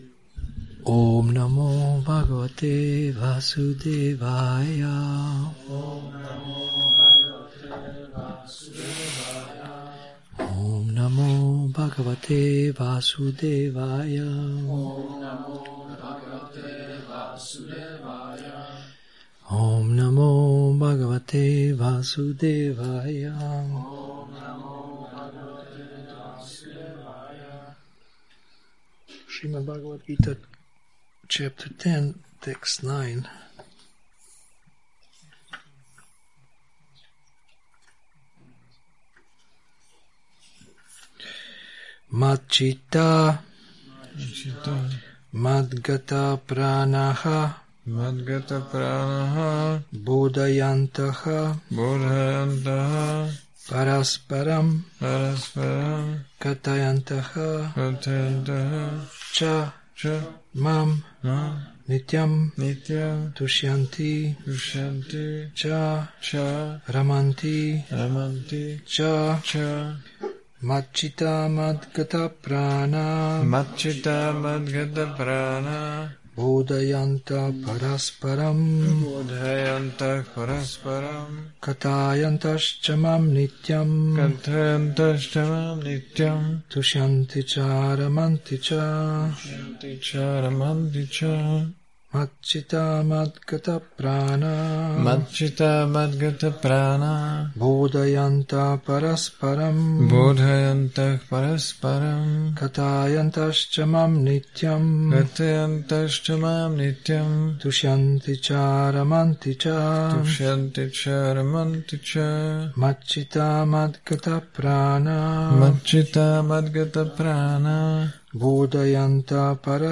Přednáška BG-10.9 – Šrí Šrí Nitái Navadvípačandra mandir